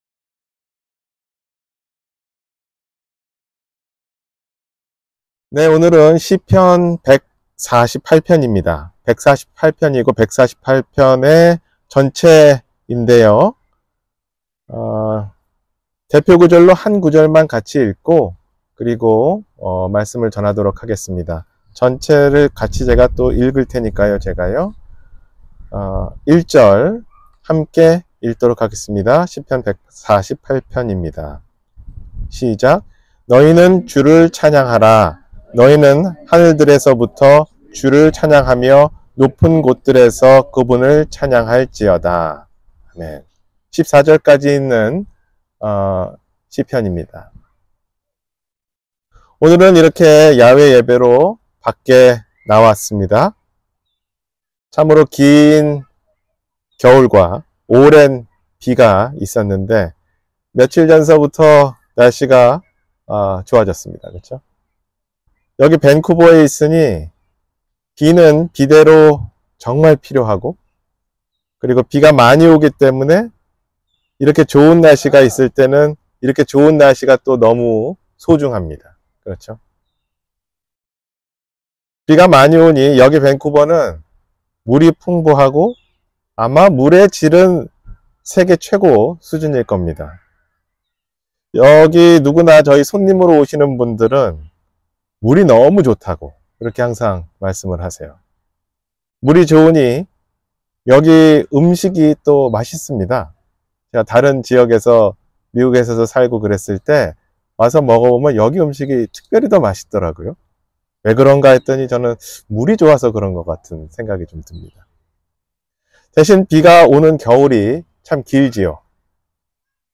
존재의 이유 – 주일설교